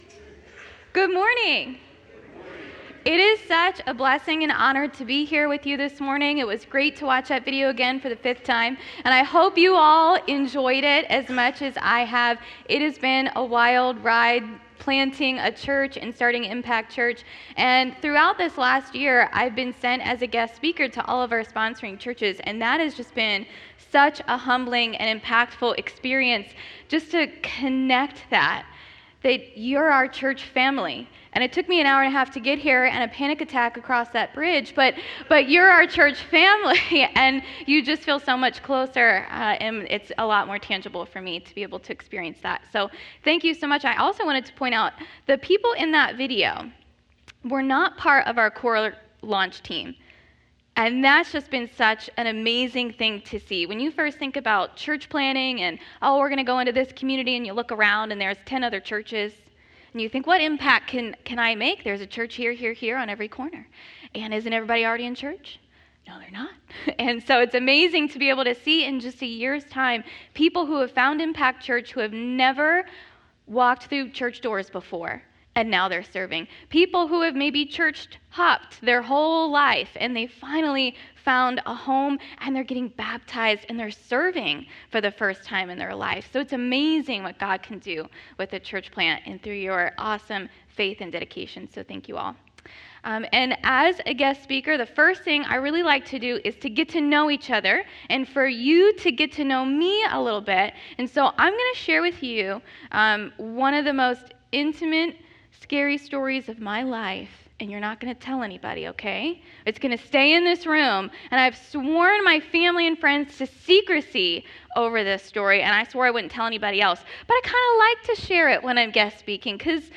Service Type: Sunday Mornings